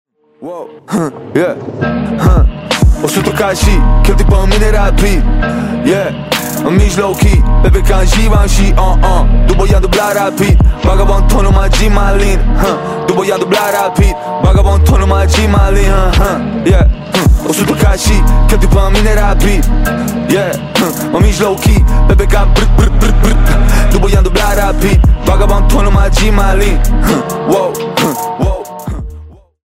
Categorie: Trap